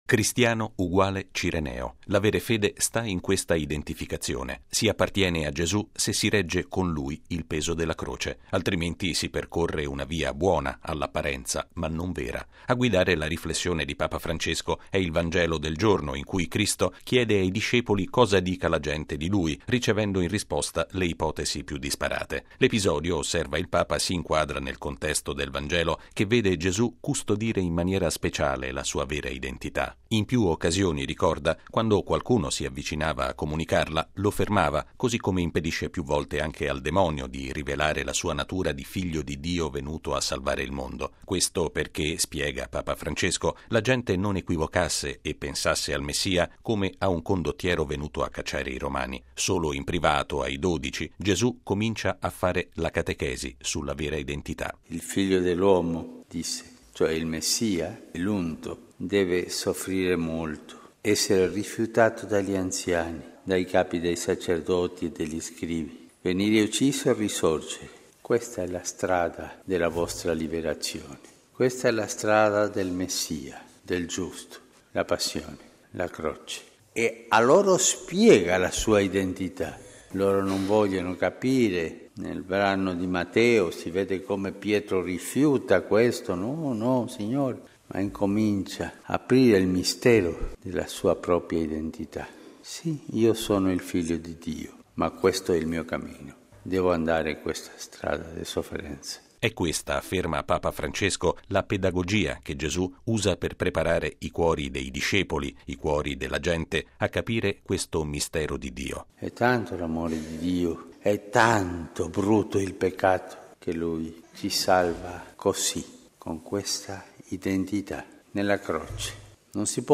Lo ha affermato Papa Francesco all’omelia della Messa mattutina celebrata nella cappella di Casa Santa Marta. Il servizio